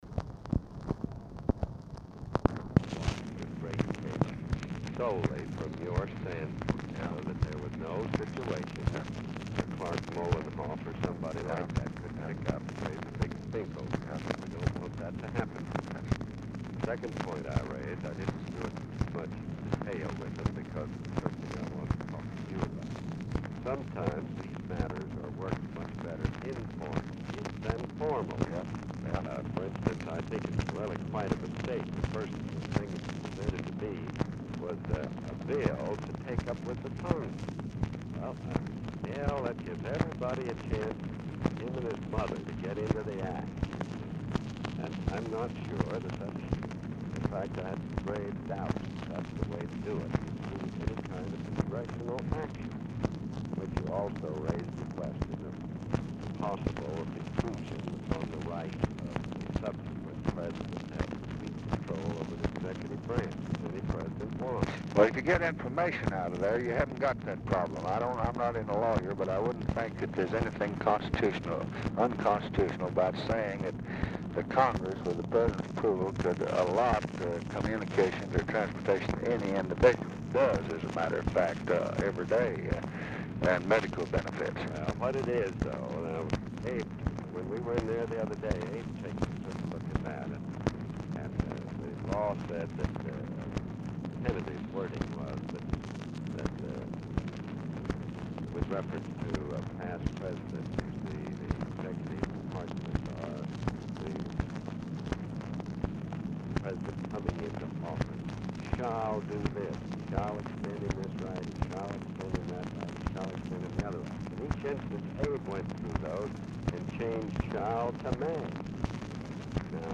Telephone conversation # 13009, sound recording, LBJ and CLARK CLIFFORD, 5/7/1968, 5:08PM?
Format Dictation belt
Location Of Speaker 1 Mansion, White House, Washington, DC
Specific Item Type Telephone conversation